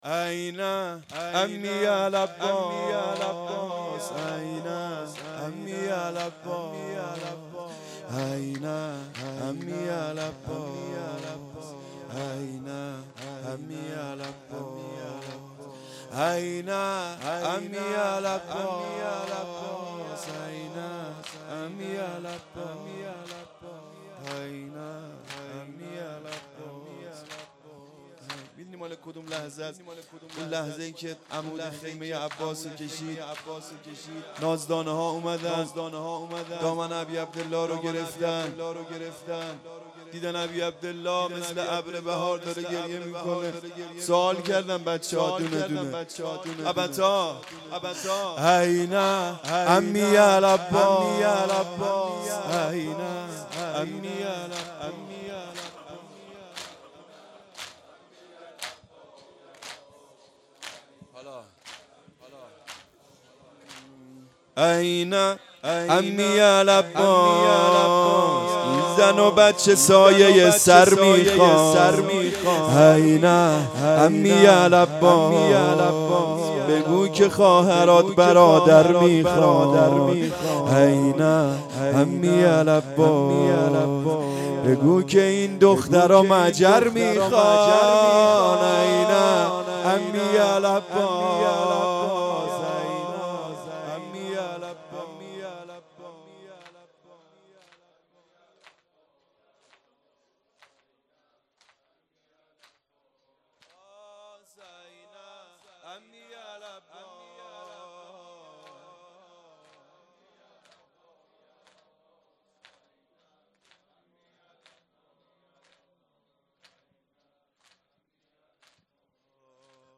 این امی العباس _ زمینه
محرم 1440 _ شب نهم